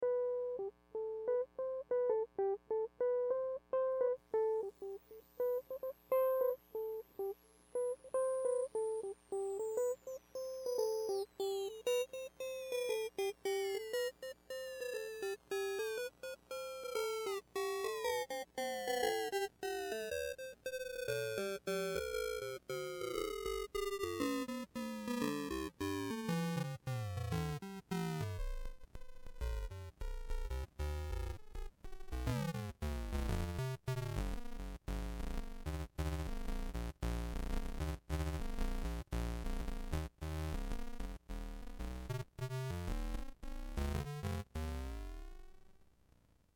If you're into Ring-Mods and Multiplying Octavers you may like the sound of this thing ... when the sampling frequency is set high enough chords come out sounding not so screwed up as they do with the two other modulator category circuits ... by varying the Sampling Freek knob we indirectly control the amount of wave-screwing that occurs ...
Wurlitzer 270 >> Nyquist Aliaser >> Korg D1600
Nyquist sweep wurly.mp3